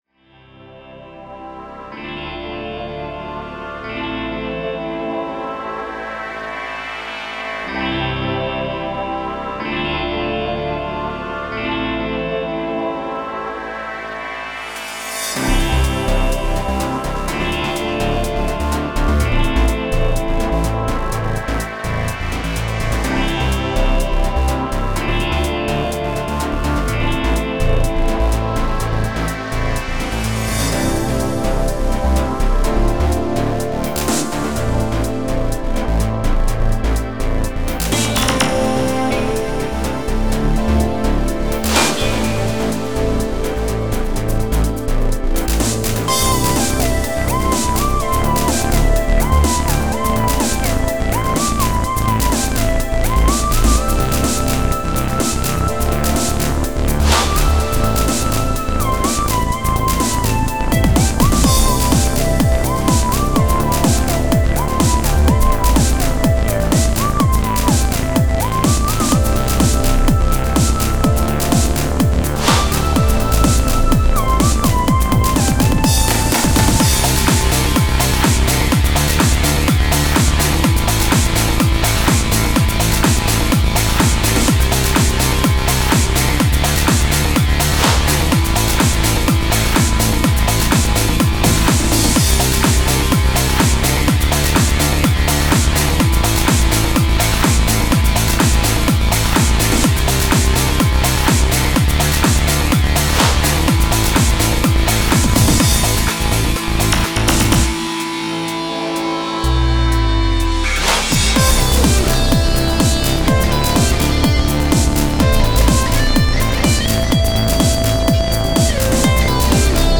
Stil: Electronic